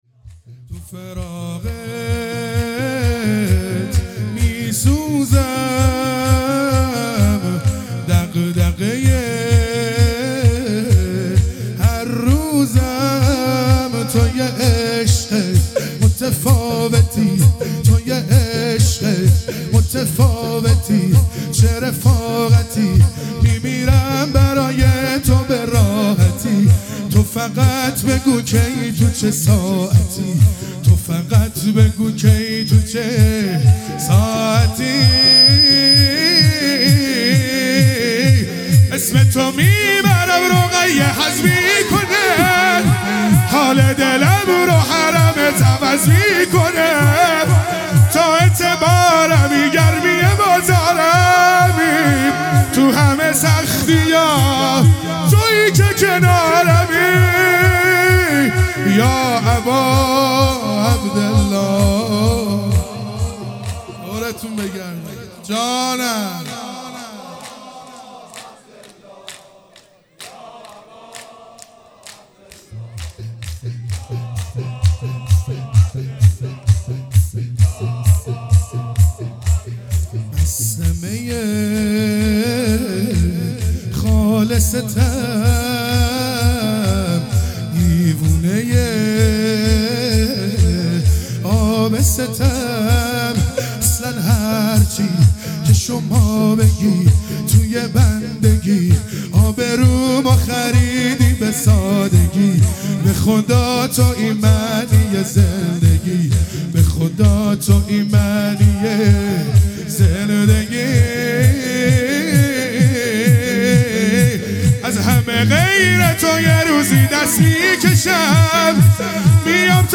دهه اول محرم الحرام | شب ششم | شور | تو فراقت میسوزم